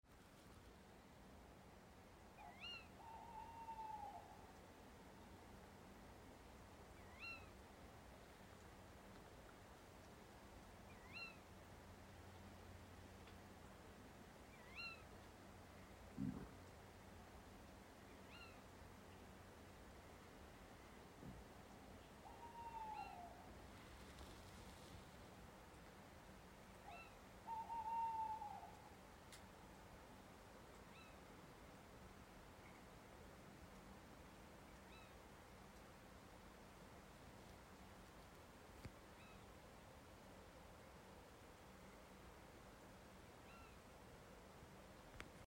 A podcast where audio-makers stand silently in fields (or things that could be broadly interpreted as fields).